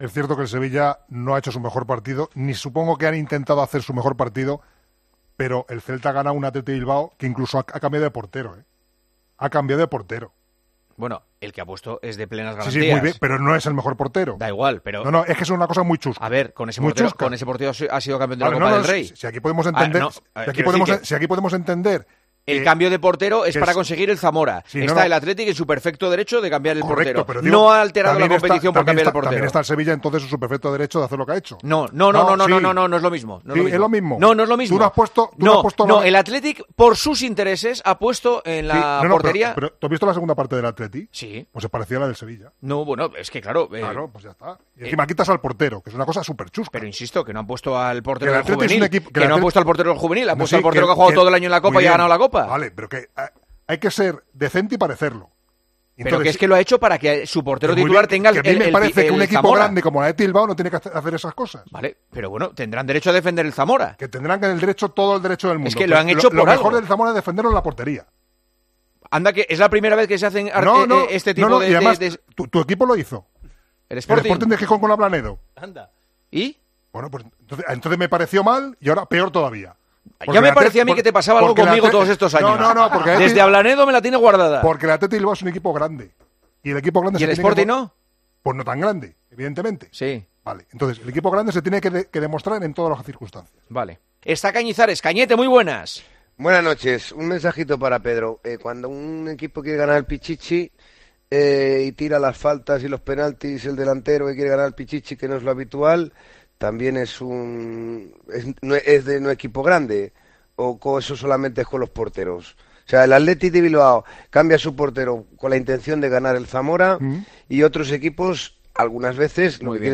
AUDIO: En el comienzo del programa de este miércoles de El Partidazo de COPE con Juanma Castaño se debatió sobre este asunto.